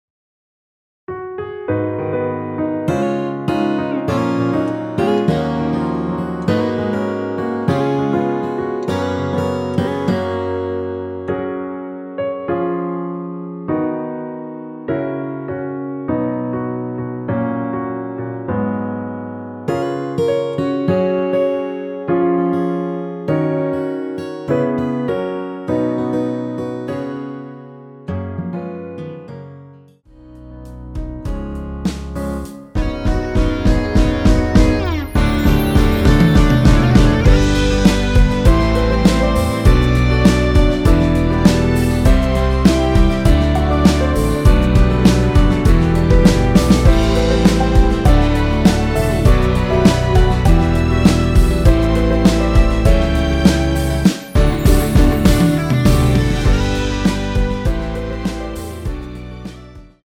원키에서(-3)내린 MR입니다.
Gb
앞부분30초, 뒷부분30초씩 편집해서 올려 드리고 있습니다.
중간에 음이 끈어지고 다시 나오는 이유는